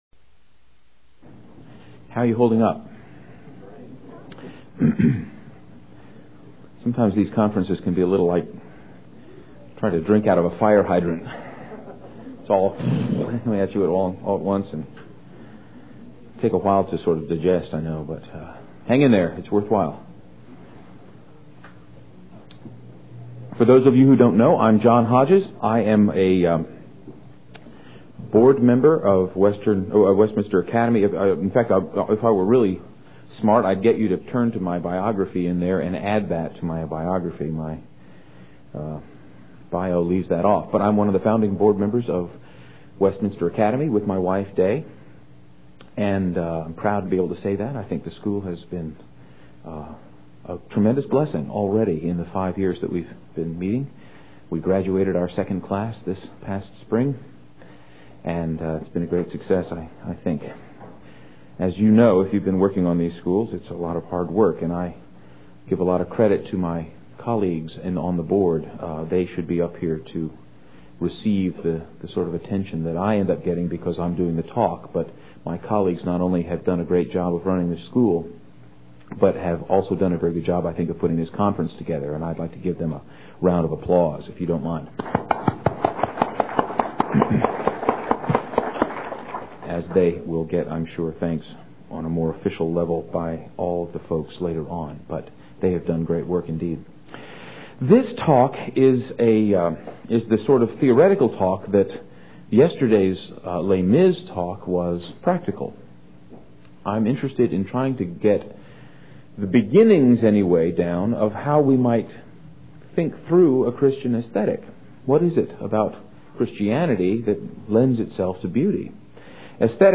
2001 Workshop Talk | 0:54:27 | All Grade Levels, Art & Music